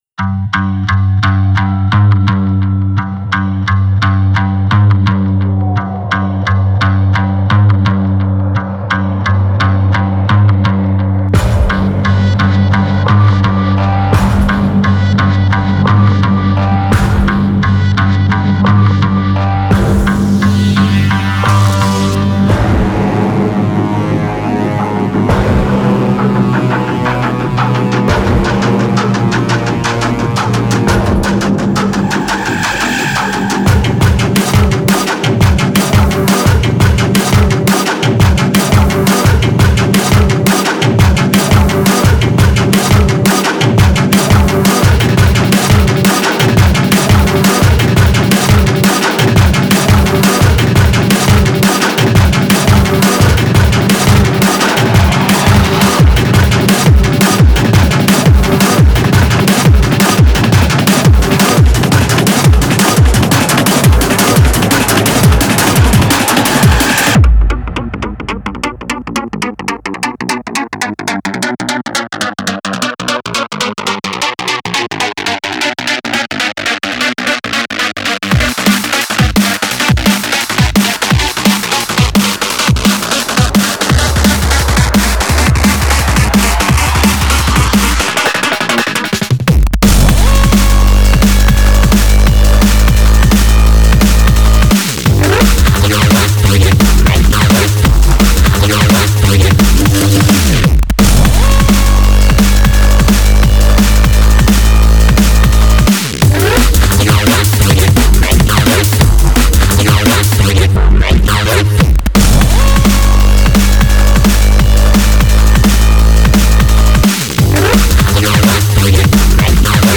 Style: Dubstep, Drum & Bass